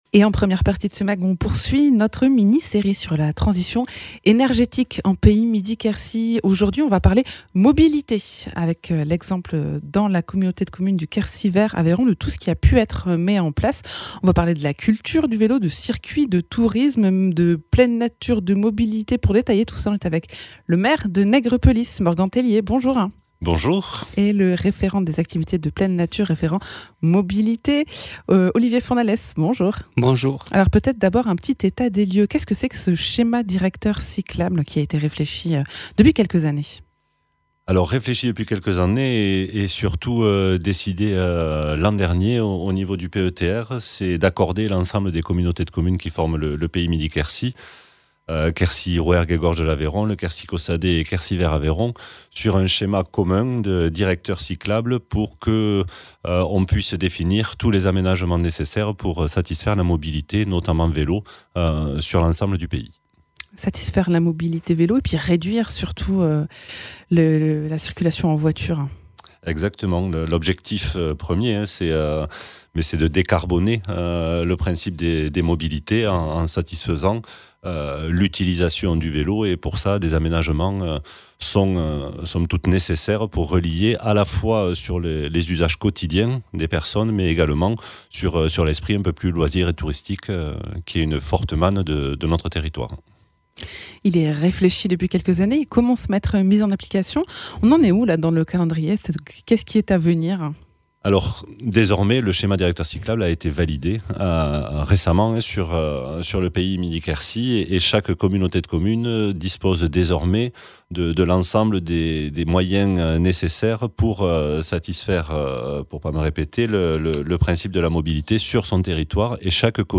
Invité(s) : Morgan Tellier, maire de Nègrepelisse